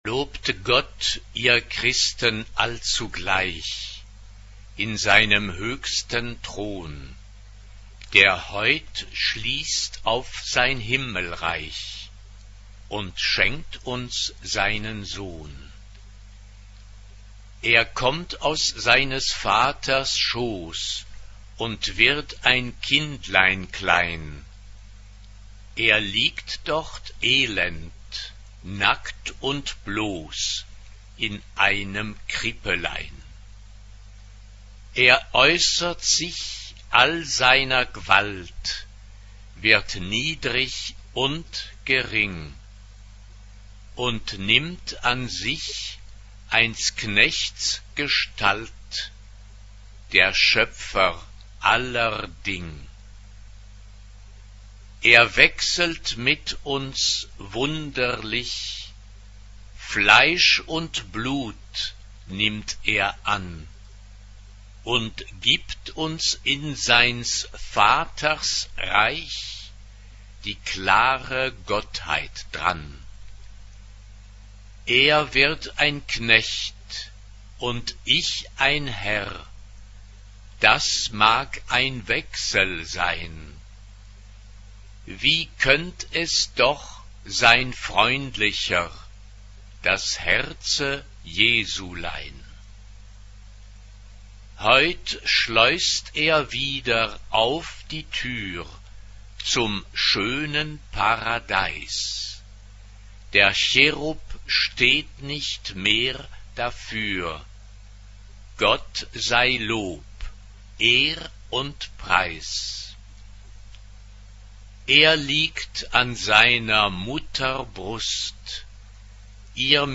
Genre-Style-Form: Sacred ; Chorale ; Romantic Mood of the piece: moderate Type of Choir: SSA (3 women voices )
Tonality: G major